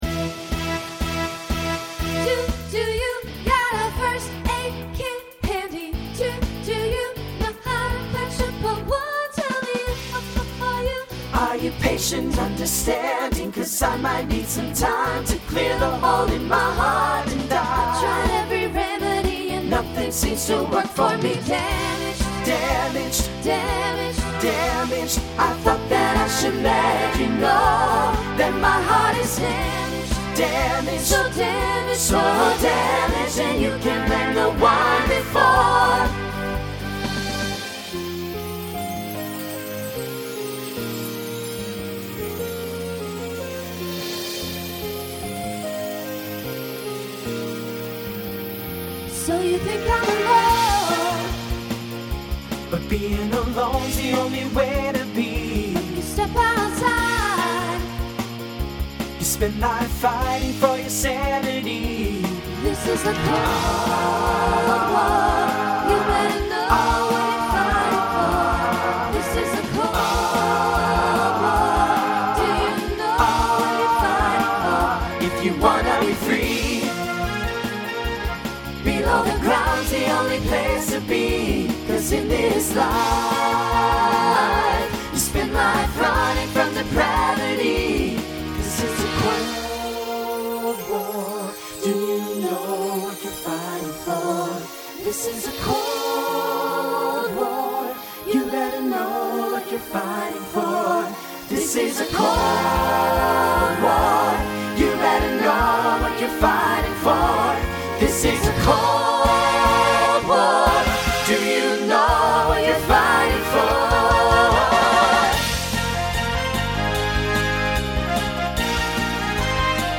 Voicing SATB Instrumental combo Genre Broadway/Film , Rock
2010s Show Function Mid-tempo